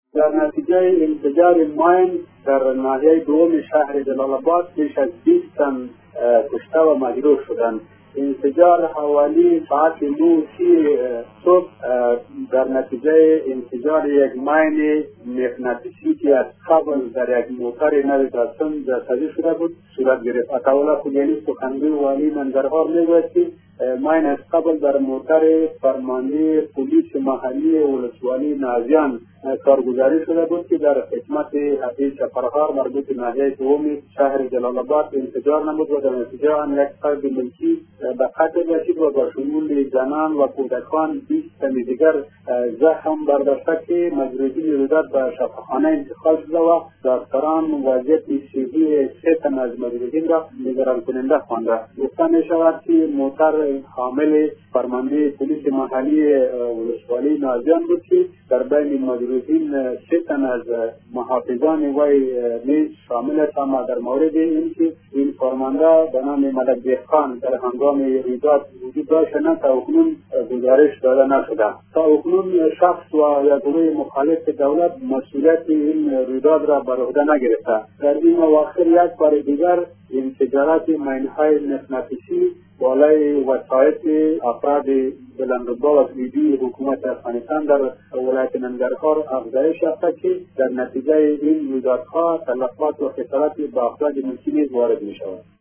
جزئیات بیشتر درگزارش خبرنگار رادیودری: